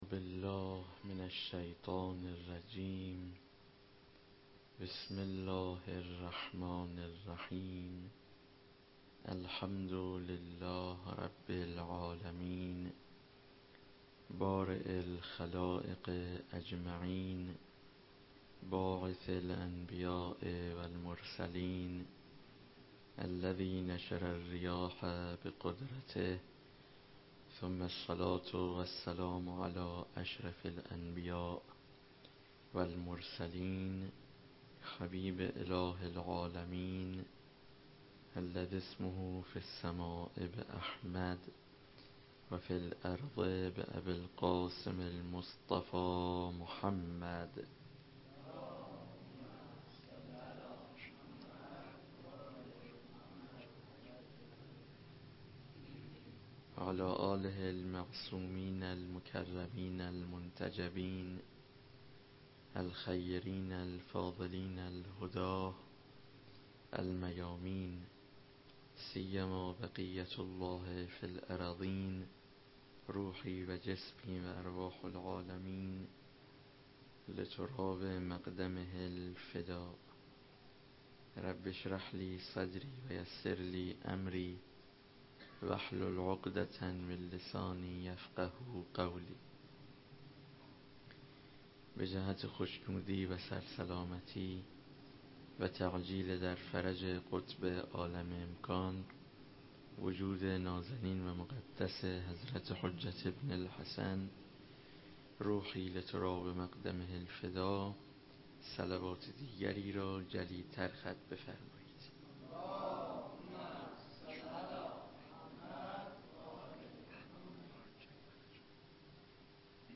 سخنرانی-روضه